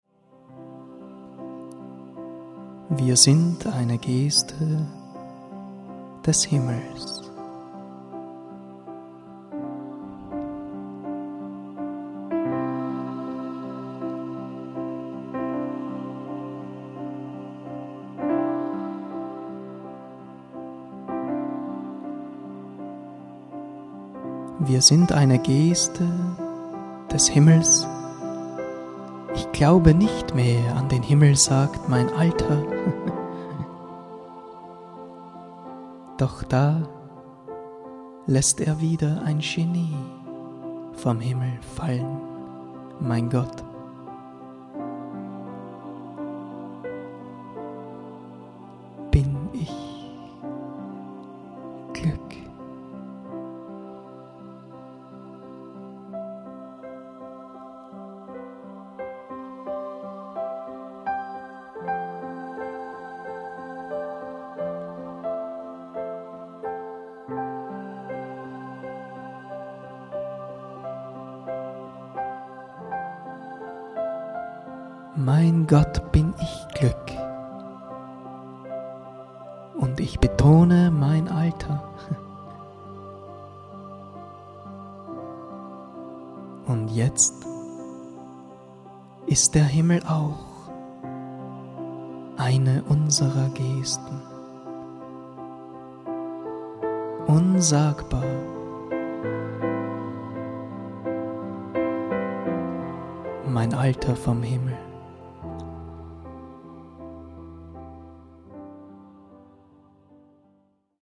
Improvisations-Poesie-Duett